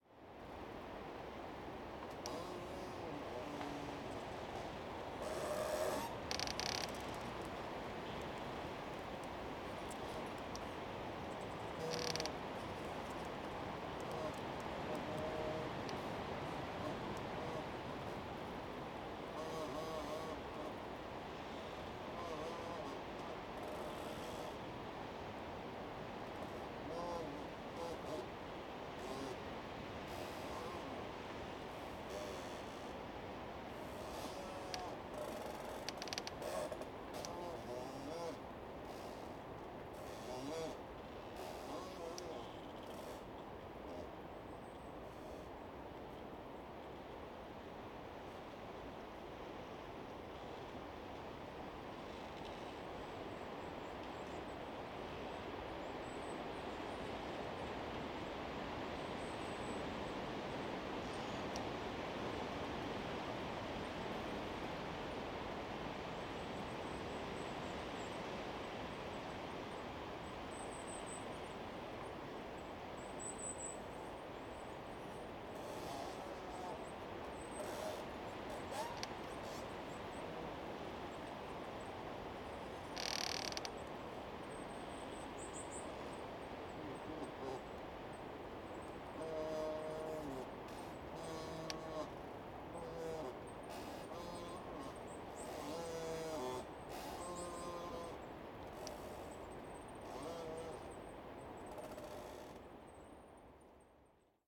Forest Wind
Forest-Wind.mp3